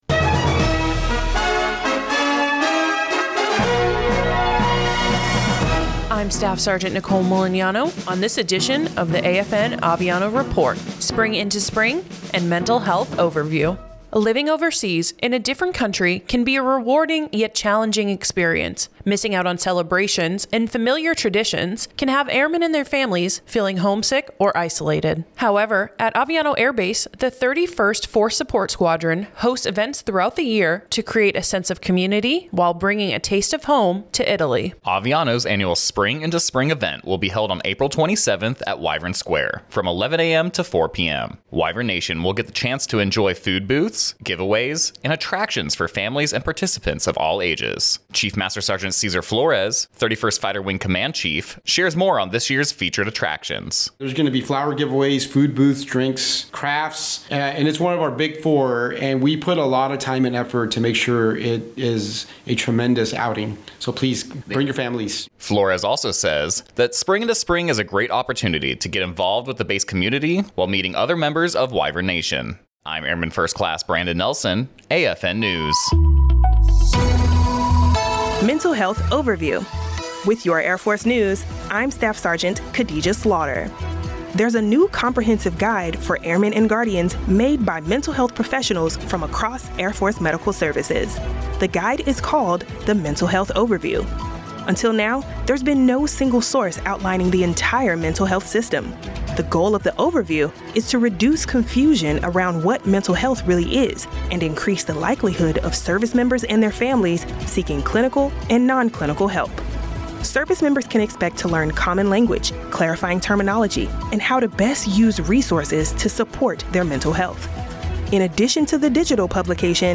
American Forces Network (AFN) Aviano radio news reports on the upcoming Spring into Spring event hosted by the Community Center at Aviano Air Base.